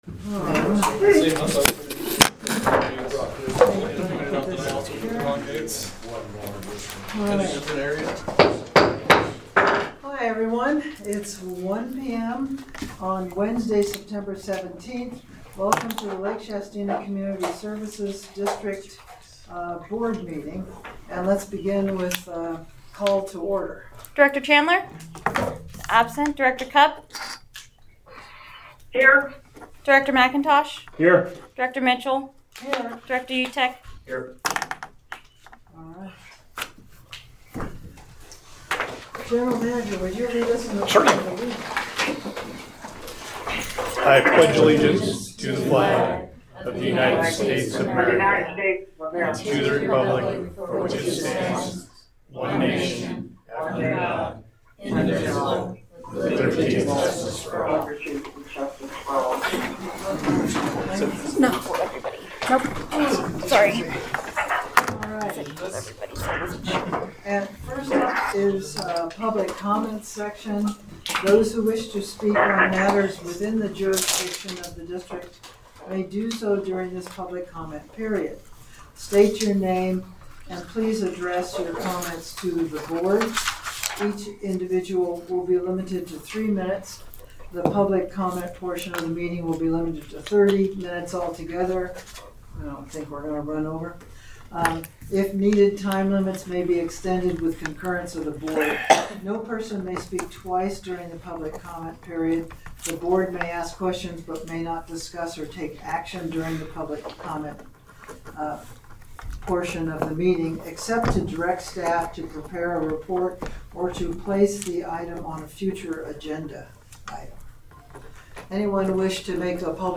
The LSCSD Board of Directors meets monthly on the third Wednesday at 1:00 p.m. at the Administration Building.
Board Meeting